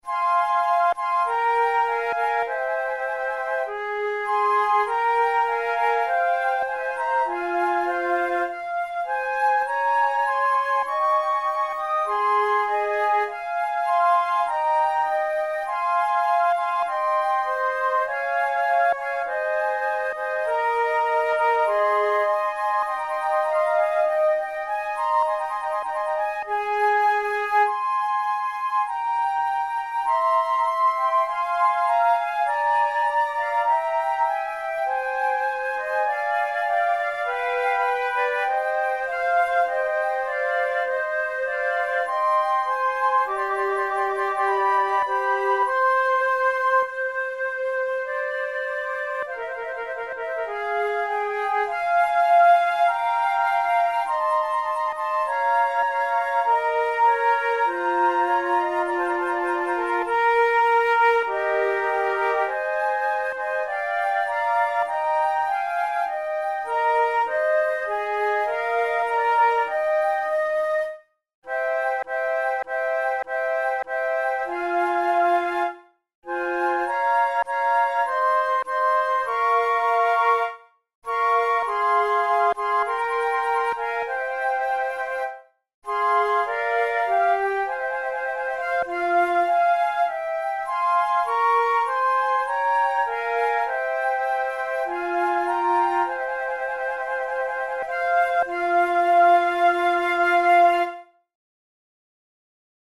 InstrumentationFlute trio
KeyF minor
RangeF4–Eb6
Time signature4/4
Tempo50 BPM
Baroque, Sonatas, Written for Flute